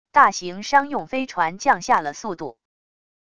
大型商用飞船降下了速度wav音频